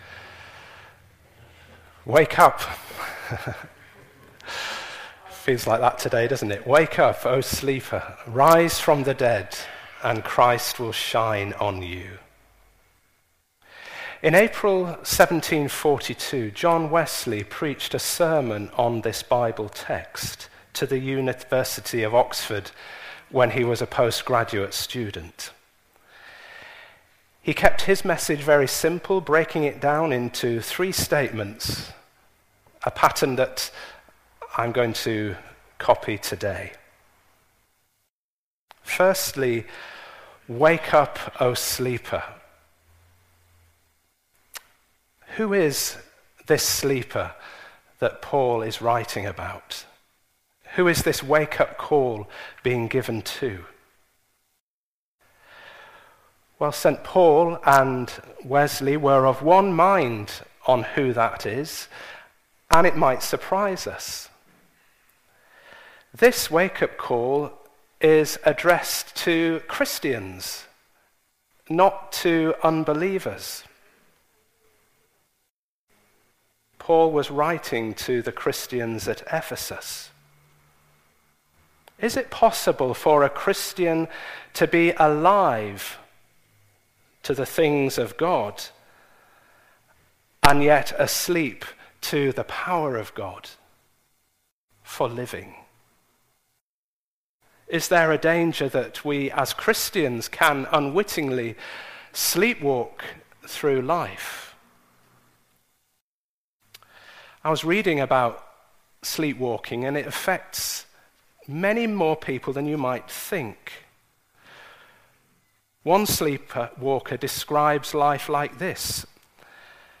The sermon is also available as an audio file.